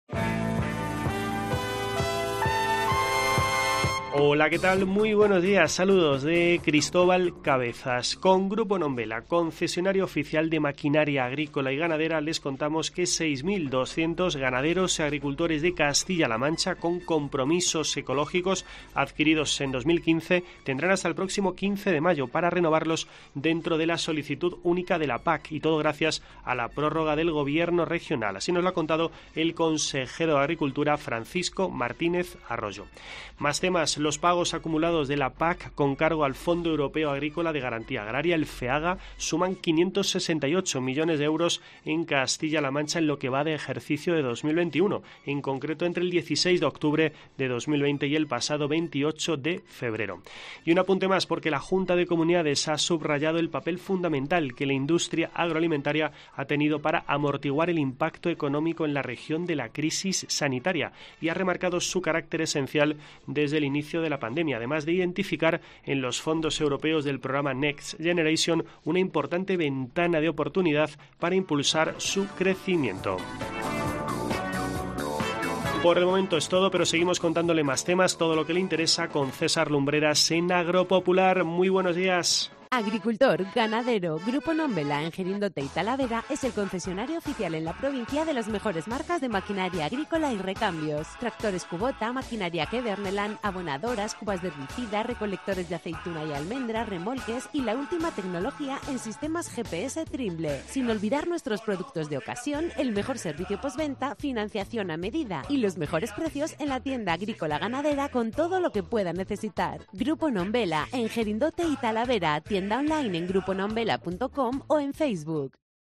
Escucha en la parte superior de esta noticia toda la actualidad del mundo del campo en nuestro boletín informativo semanal.